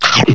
00162_Sound_MORDIDA.mp3